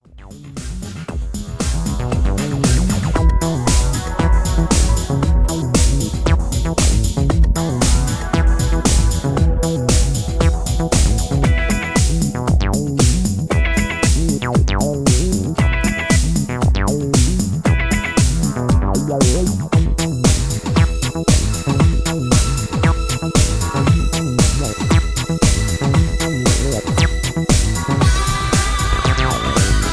Background music suitable for TV/Film use.
Tags: techno